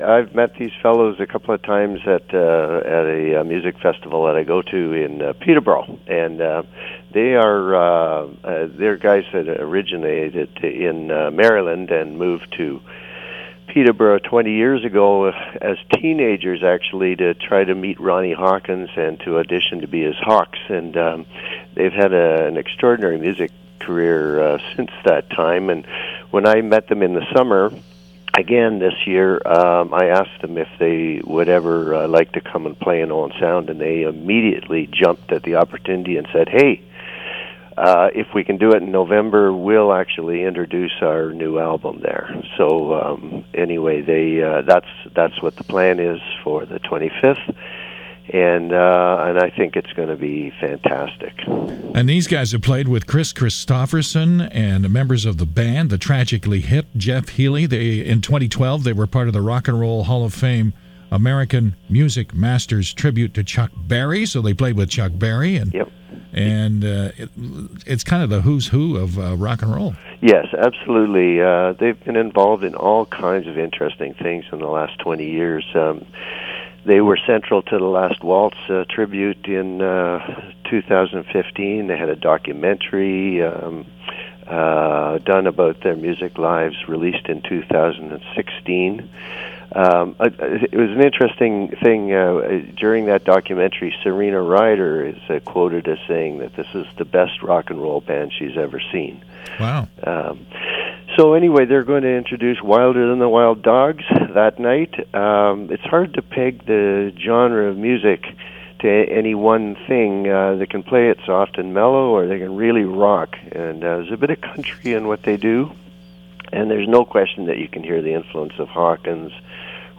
was a guest